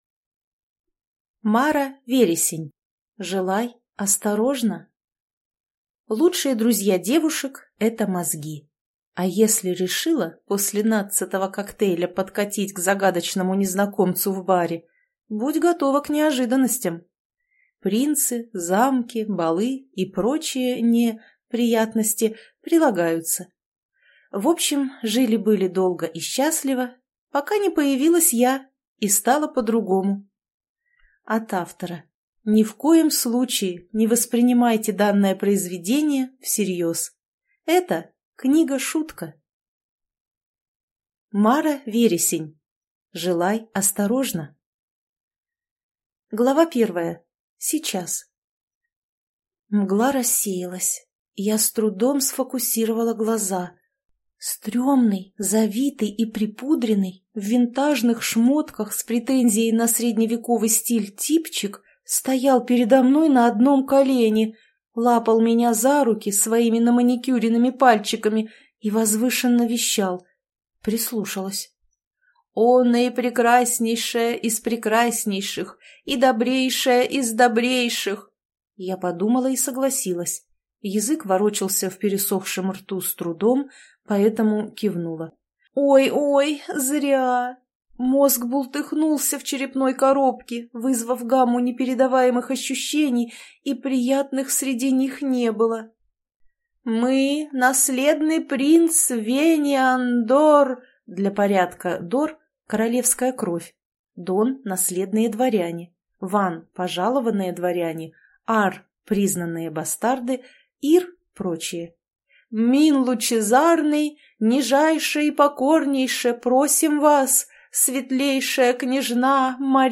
Аудиокнига Желай осторожно | Библиотека аудиокниг